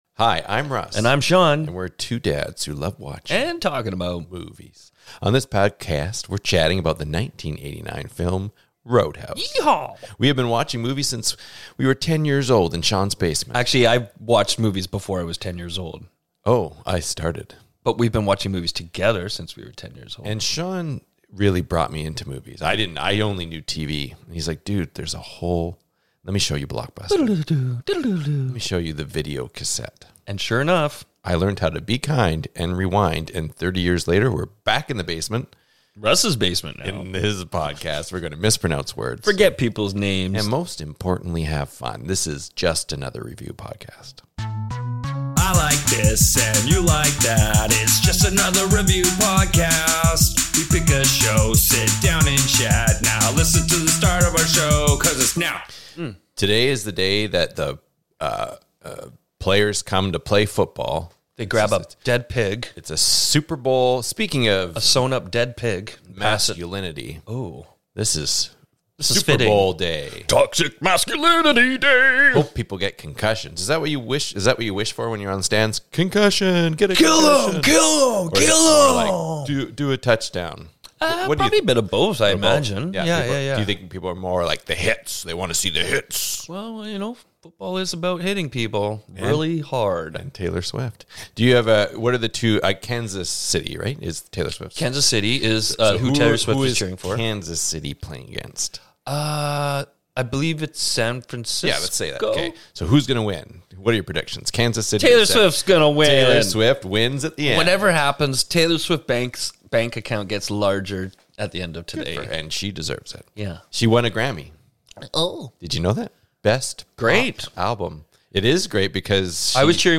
The two Coolers mispronounce words, forget actors names, and most importantly have fun. This is Just Another Review Podcast. If you like ASMR, you'll love this episode.